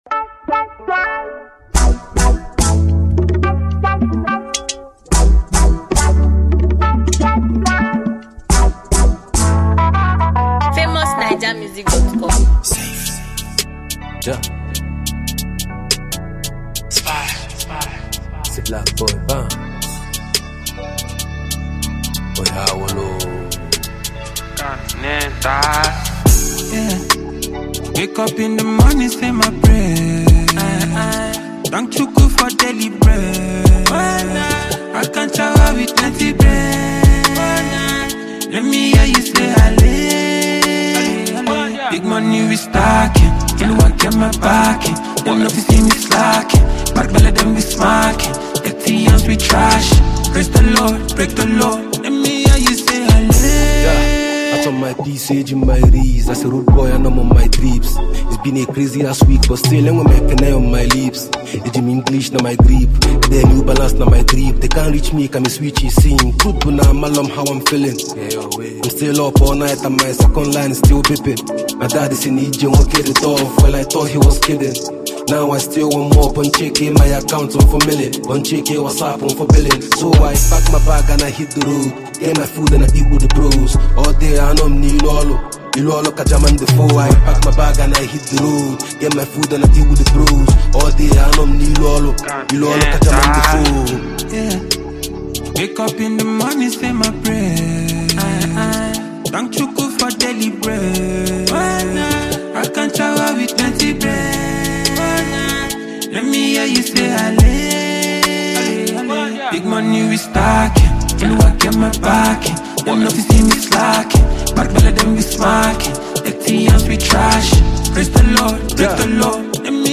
afro-dancehall
skilled Nigerian native rapper